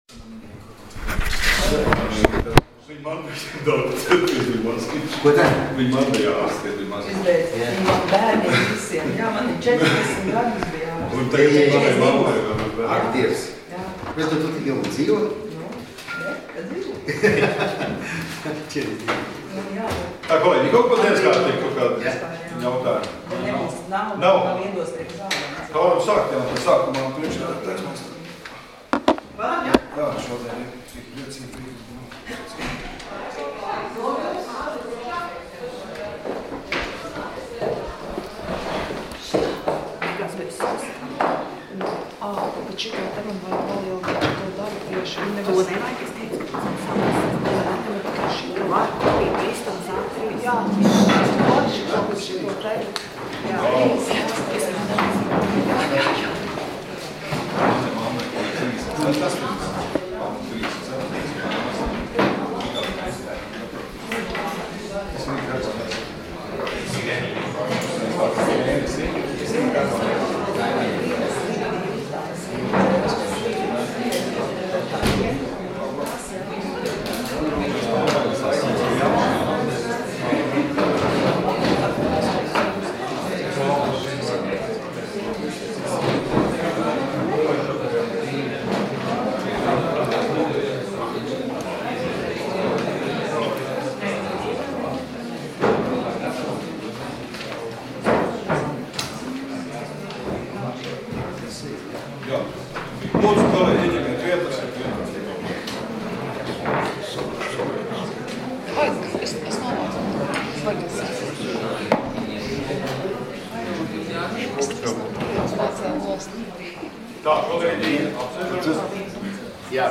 Domes sēdes 31.03.2017. audioieraksts